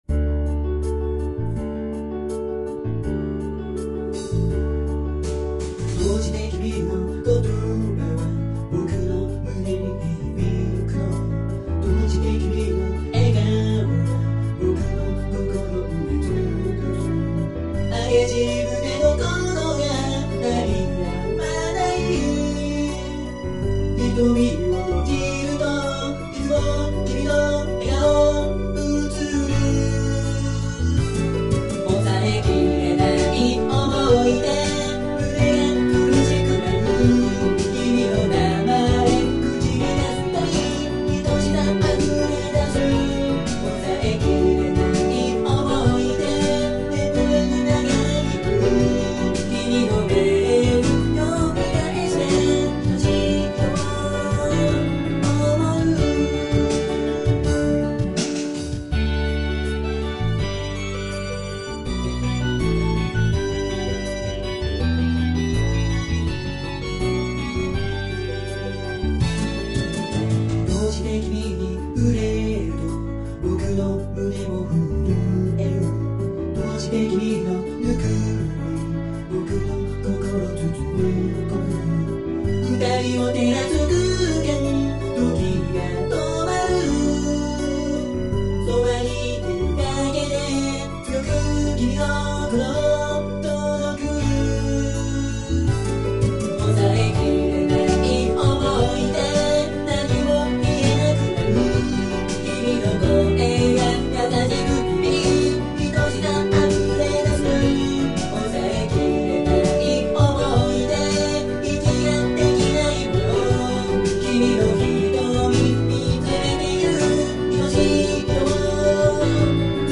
【1.7倍速】